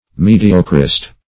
Mediocrist \Me"di*o`crist\